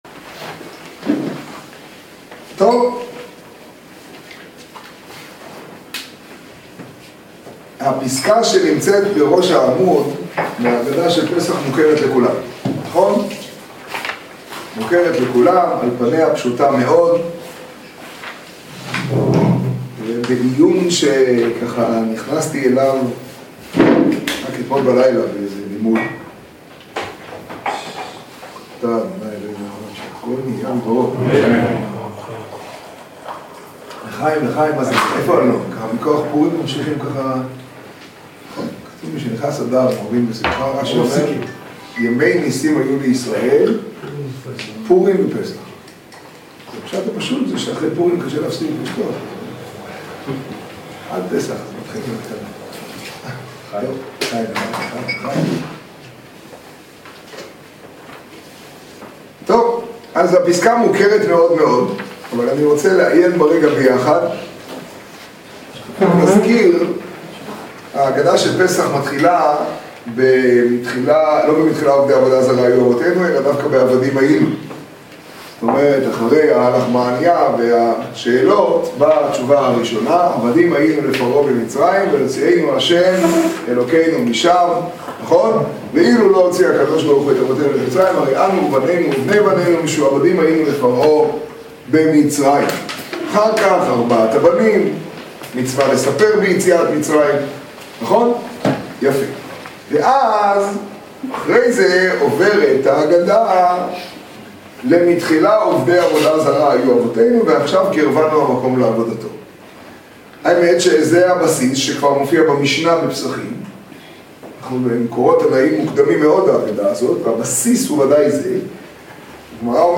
השיעור בפתח תקוה, פרשת שמיני תשעד.